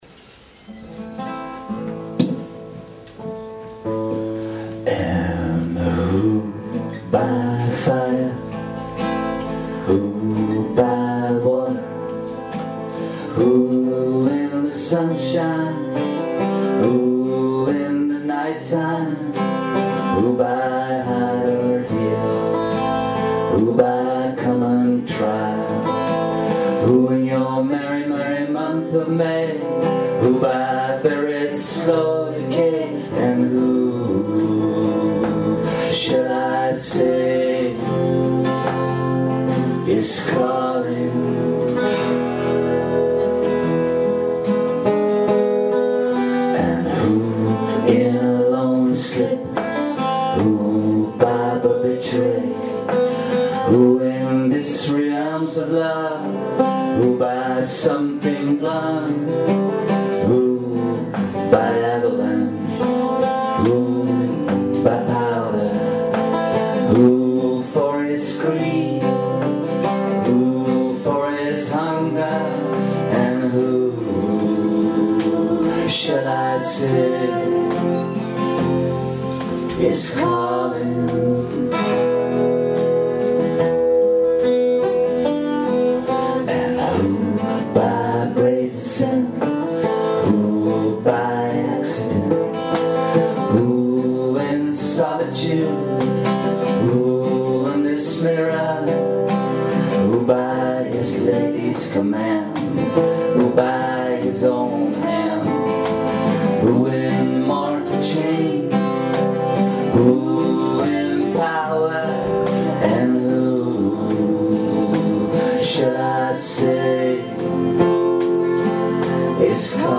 in the record shop Concerto, Amsterdam